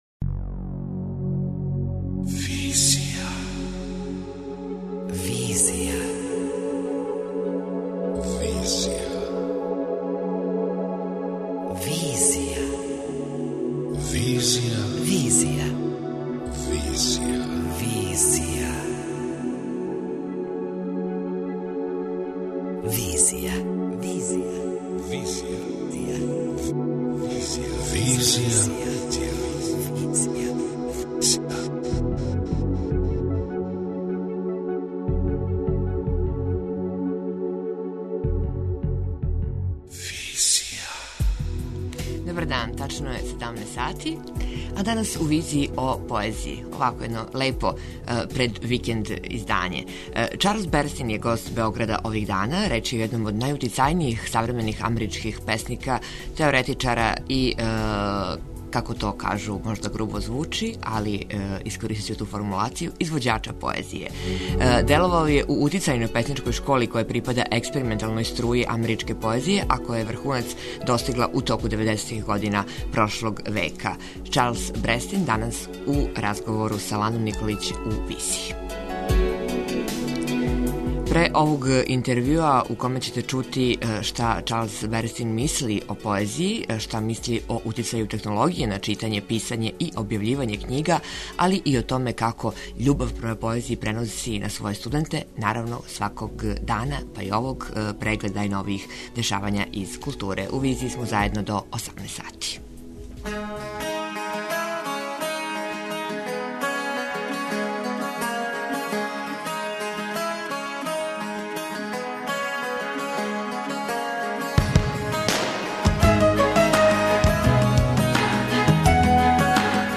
Интервју: Чарлс Берстин, амерички песник;